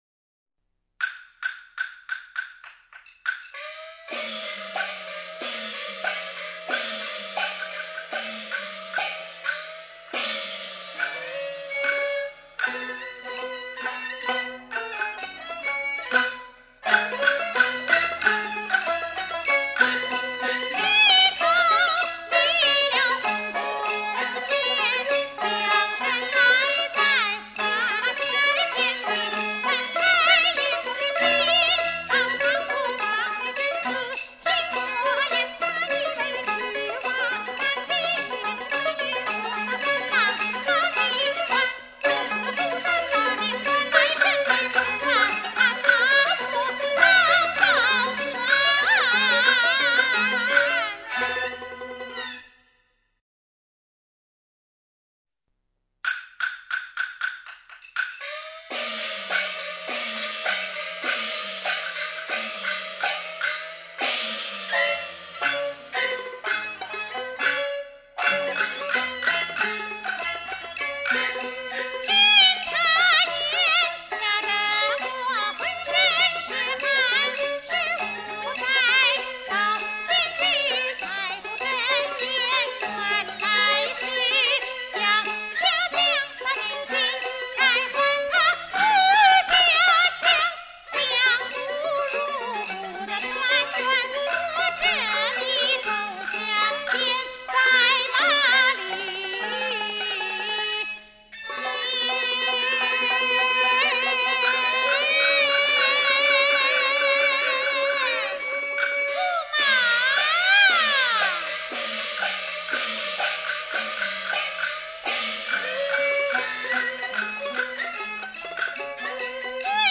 本專輯是八十年代錄音再版
西皮流水